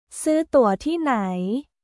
スー・トゥア・ティー・ナイ？